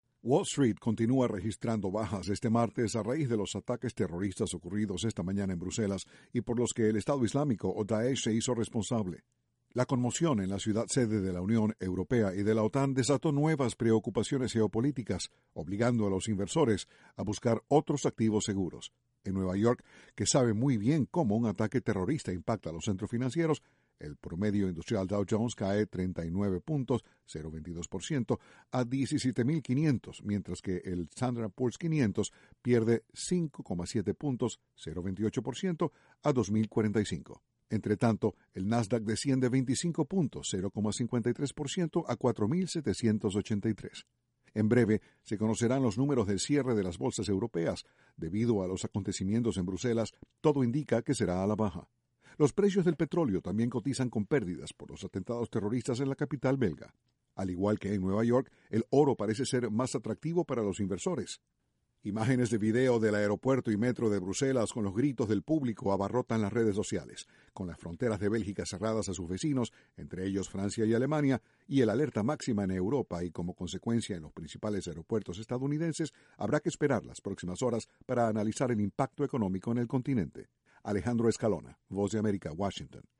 Ataques en Bruselas impactan negativamente la Bolsa de Nueva York. Desde la Voz de América, Washington